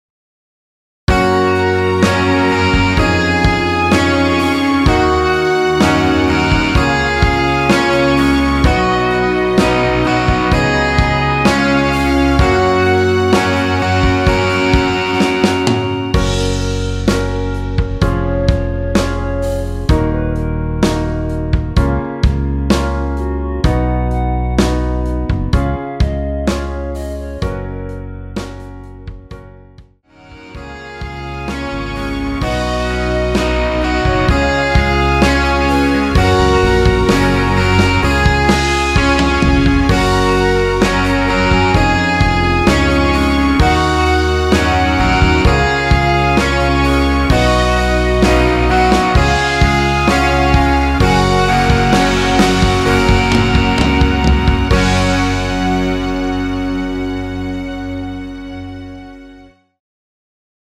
원키에서(-1)내린 멜로디 포함된 MR입니다.
F#
앞부분30초, 뒷부분30초씩 편집해서 올려 드리고 있습니다.